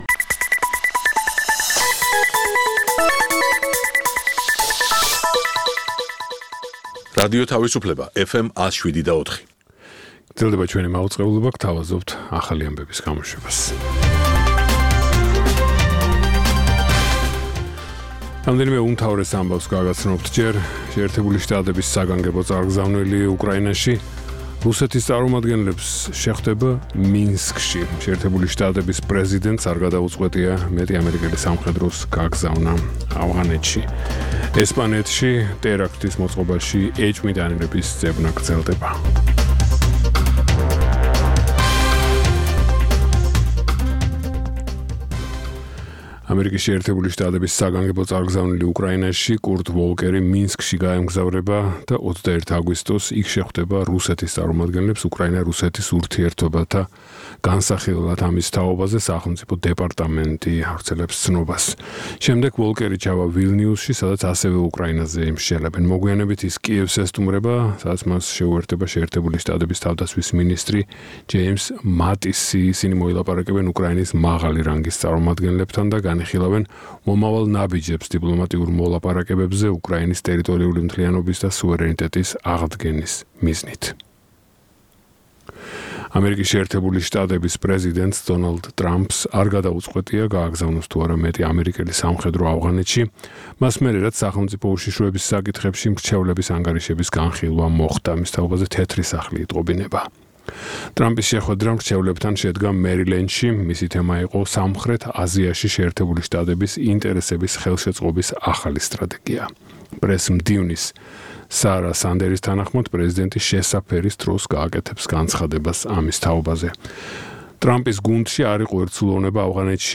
ახალი ამბები (რადიო თავისუფლება) + Music Mix ("ამერიკის ხმა")